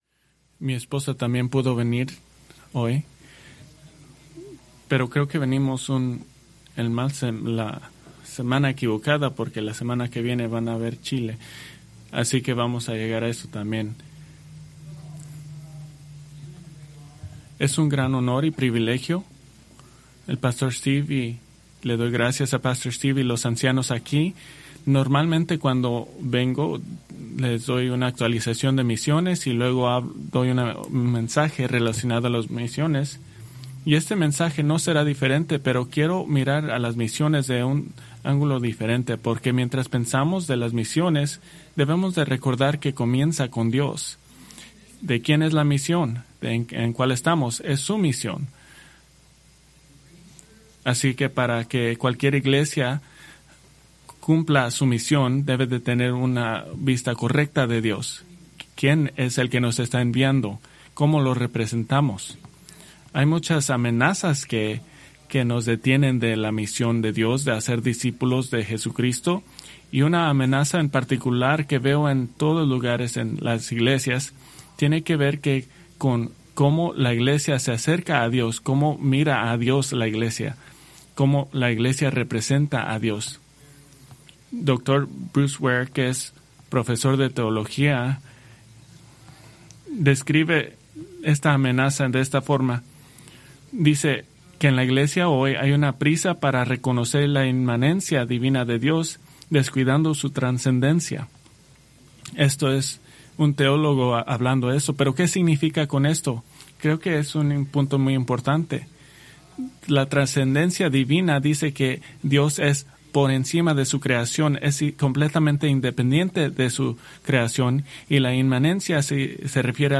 Preached January 11, 2026 from Salmo 99